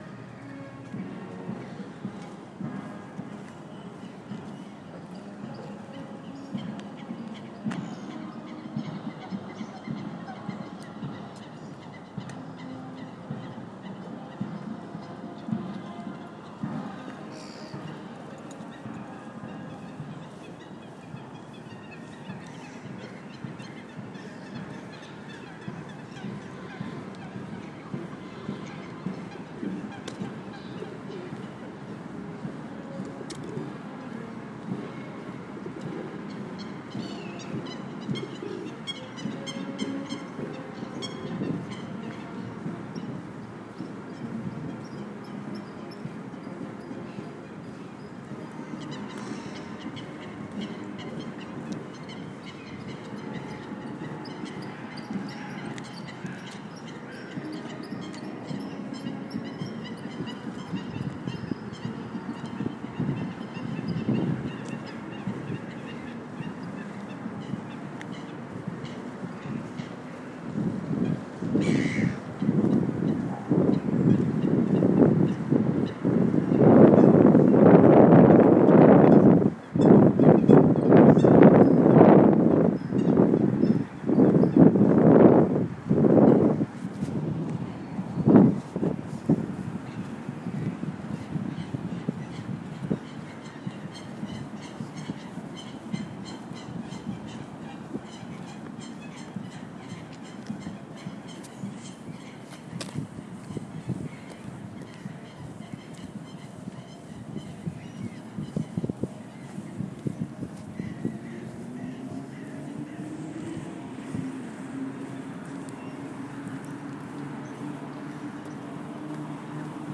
A walk to work across St.James' park